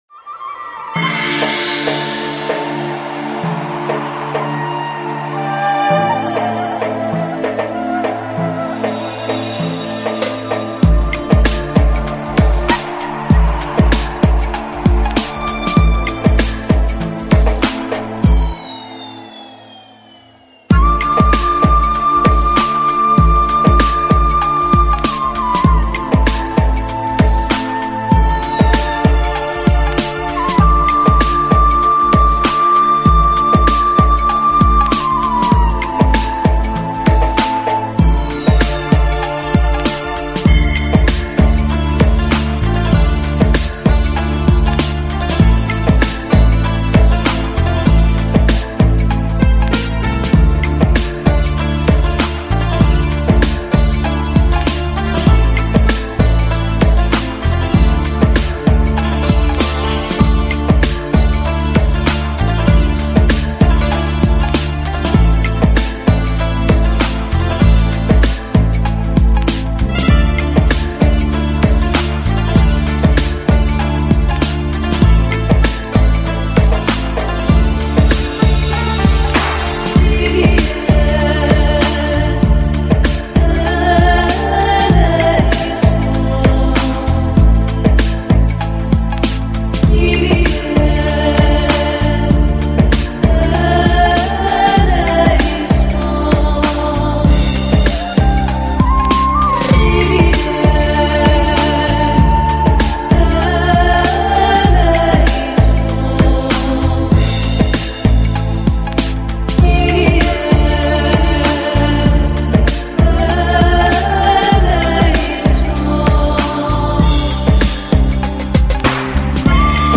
00009___Vostochnaya_muzyka___.mp3